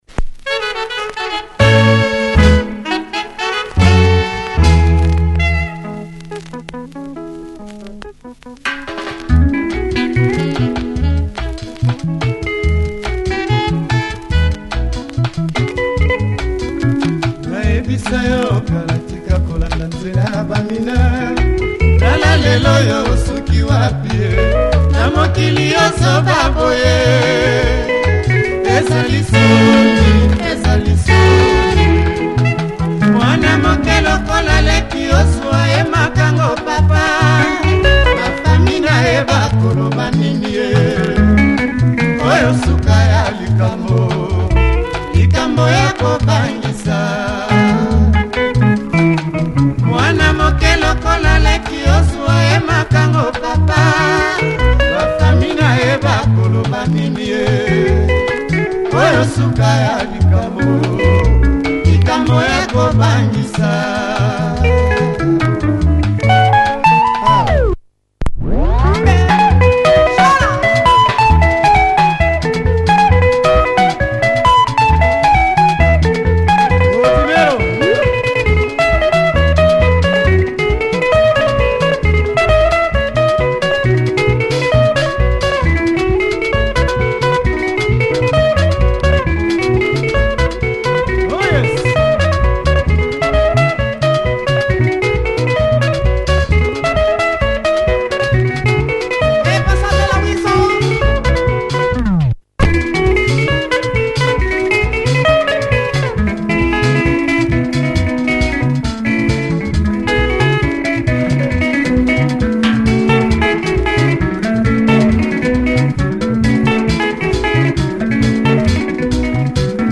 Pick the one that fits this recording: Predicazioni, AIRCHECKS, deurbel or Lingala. Lingala